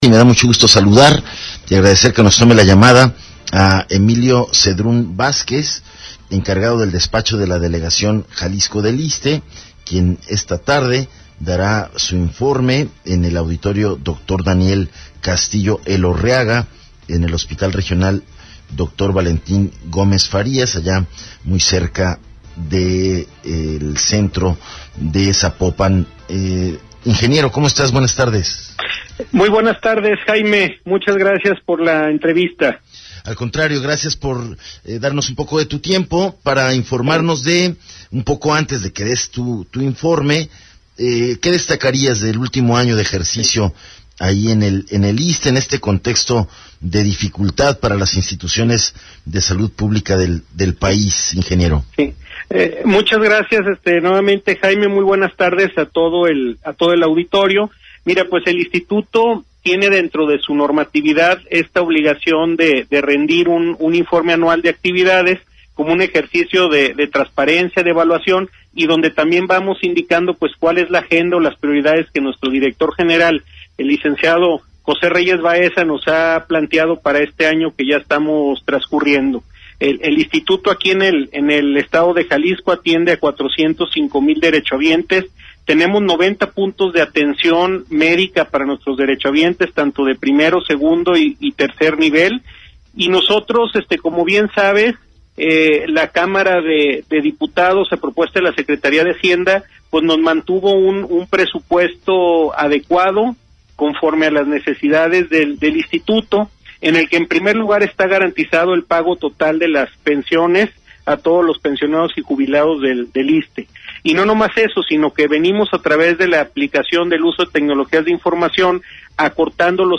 ENTREVISTA 140316